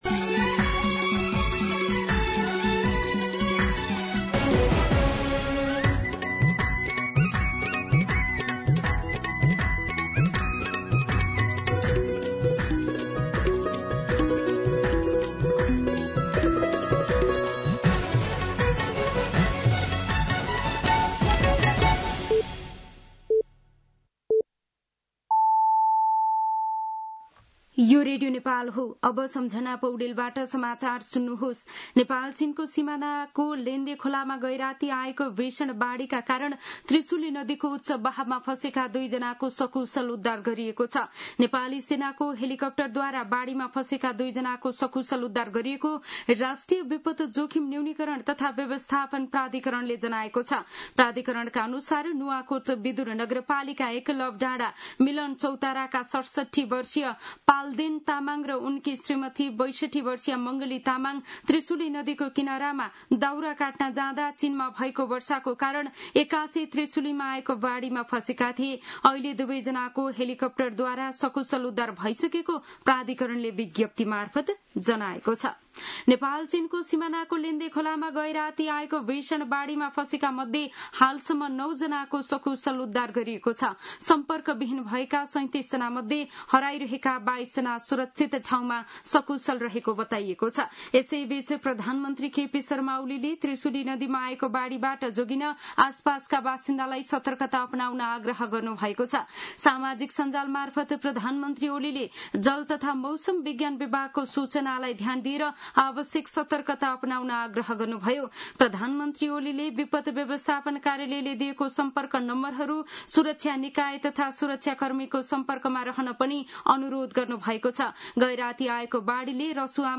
An online outlet of Nepal's national radio broadcaster
मध्यान्ह १२ बजेको नेपाली समाचार : २४ असार , २०८२